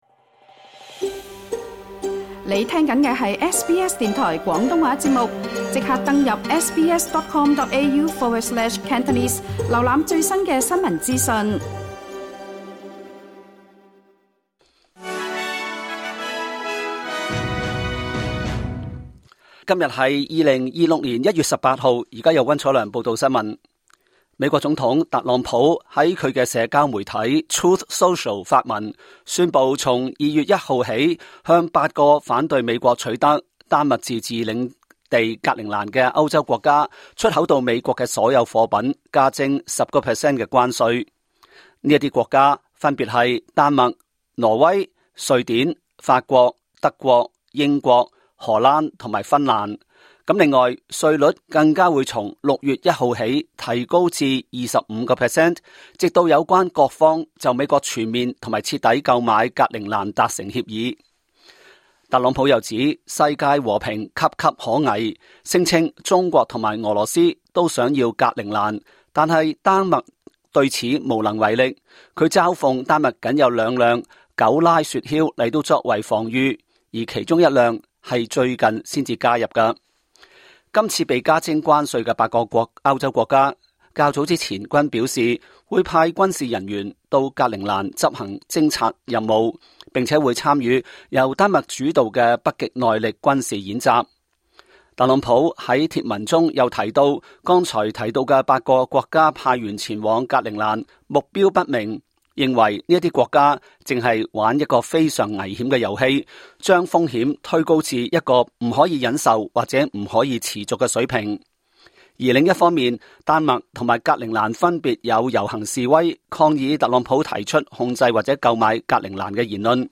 2026 年 1 月 18 日 SBS 廣東話節目詳盡早晨新聞報道。